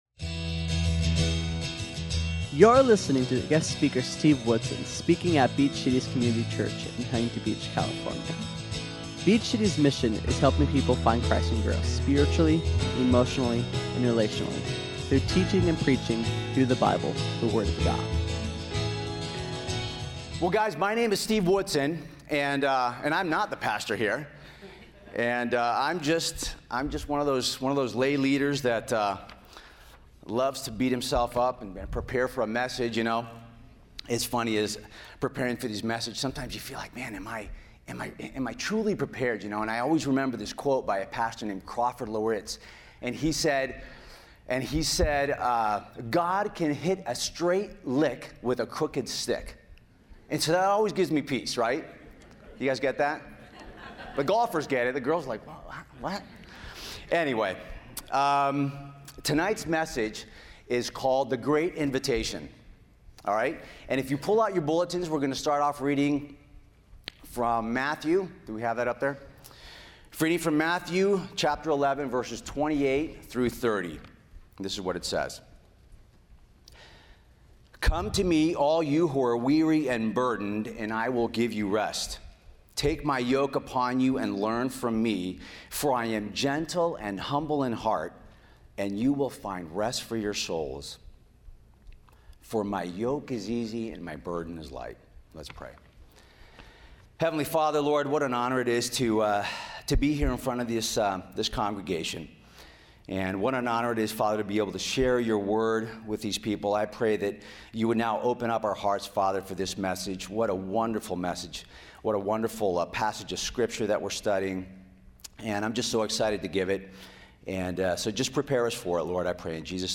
Listen in as we learn about who this invitation is for and what is the reward for taking this invitation. SERMON AUDIO: SERMON NOTES: